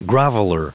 Prononciation du mot groveler en anglais (fichier audio)
Prononciation du mot : groveler